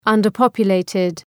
Προφορά
{,ʌndər’pɒpjʋ’leıtıd}